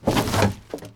household
Garbage Can Plastic Lid Off